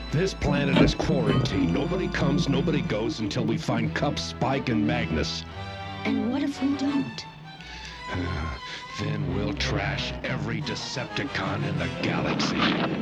Rodimus Prime Dick Gautier.mp3